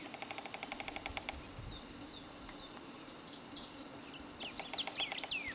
Carpintero del Cardón (Melanerpes cactorum)
Nombre en inglés: White-fronted Woodpecker
Localidad o área protegida: Colalao del Valle
Condición: Silvestre
Certeza: Observada, Vocalización Grabada
carpintero-cardon.mp3